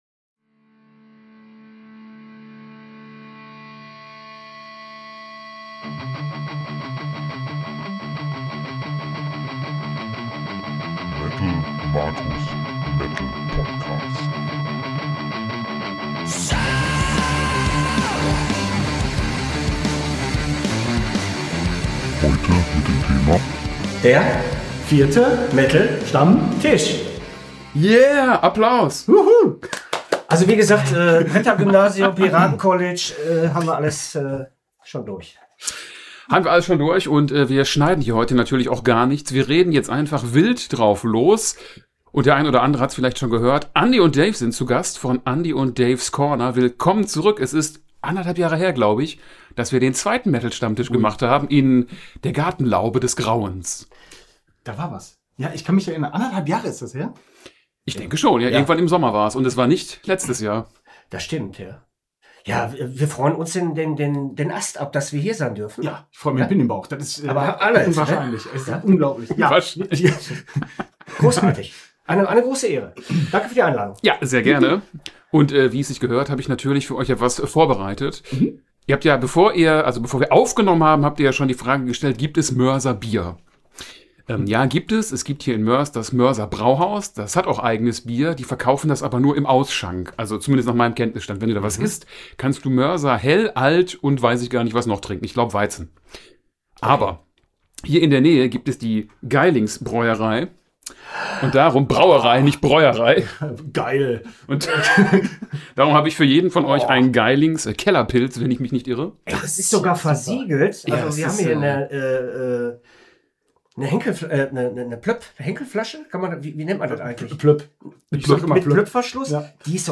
Mit unverkennbarer Ruhrpott-Schnauze quasselt man sich durch drei verschiedene Themen, denn jeder hat ein Thema im Gepäck, über das er dringend reden wollte.